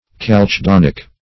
Calcedonic \Cal`ce*don"ic\
calcedonic.mp3